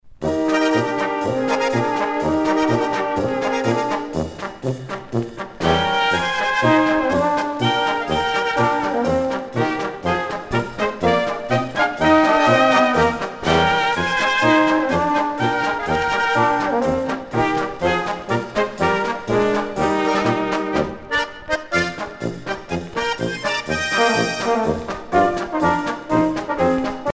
danse : marche-polka
Pièce musicale éditée